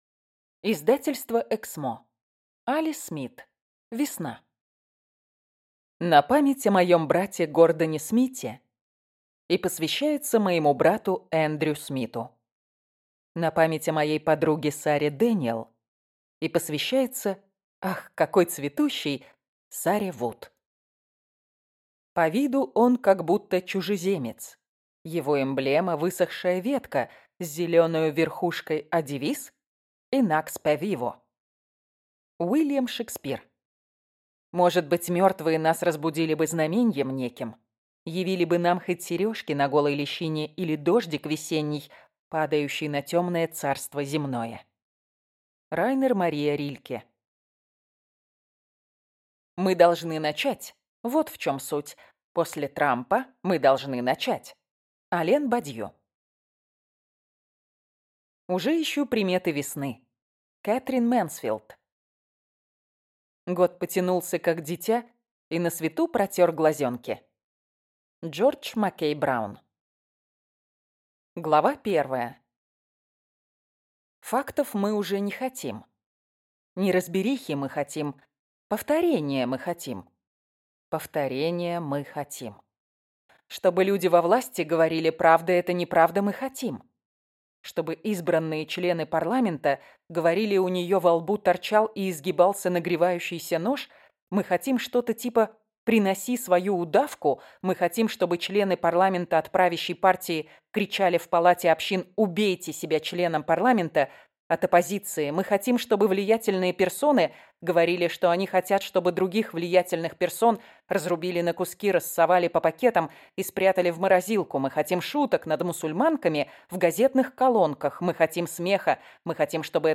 Аудиокнига Весна | Библиотека аудиокниг